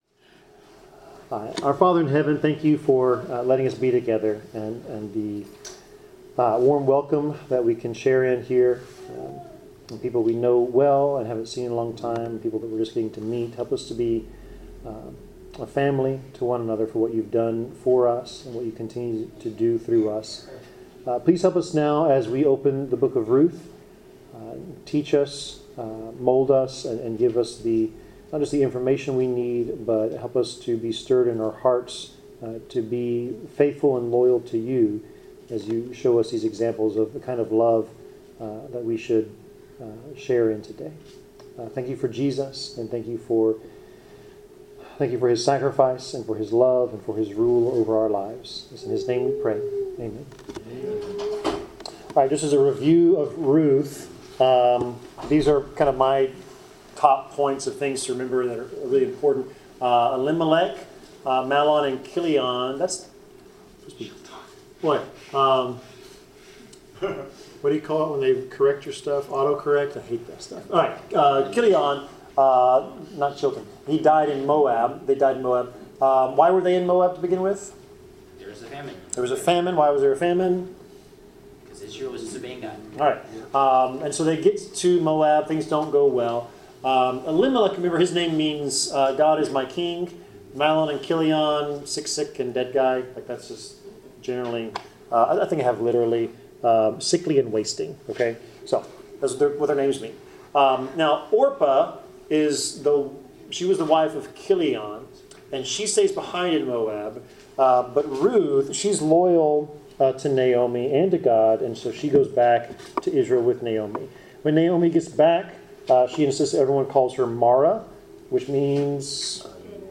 Bible class: Ruth 2
Service Type: Bible Class